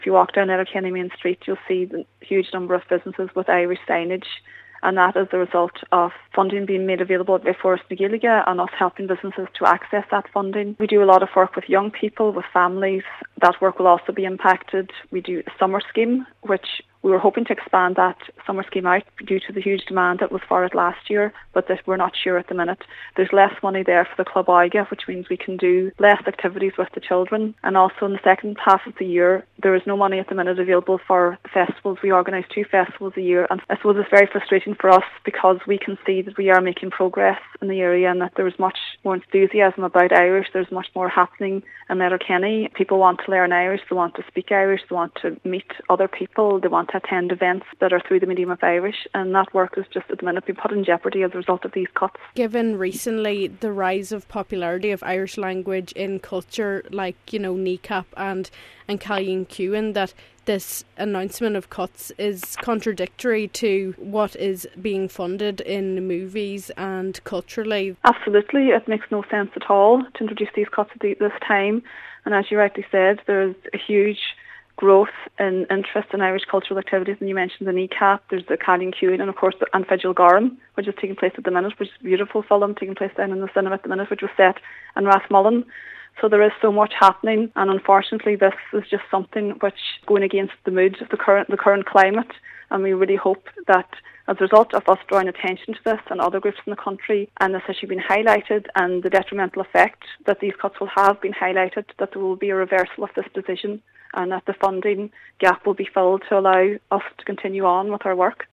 She says these cuts will be detrimental to the services they provide.